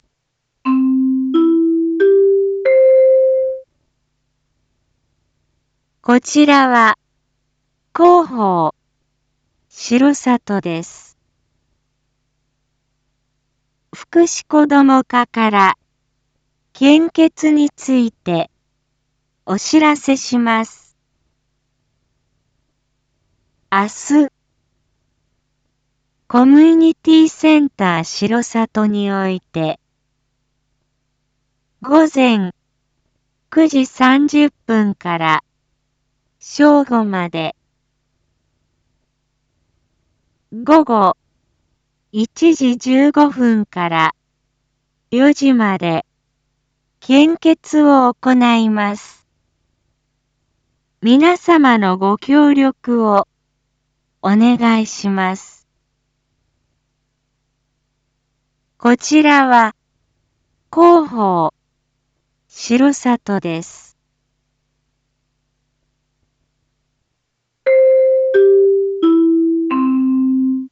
Back Home 一般放送情報 音声放送 再生 一般放送情報 登録日時：2023-09-25 19:01:12 タイトル：R5.9.25夜 献血 インフォメーション：こちらは、広報しろさとです。 福祉こども課から献血について、お知らせします。